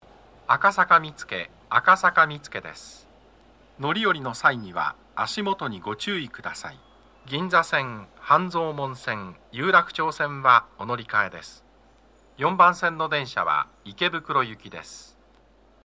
足元注意喚起放送が付帯されています。
男声
到着放送1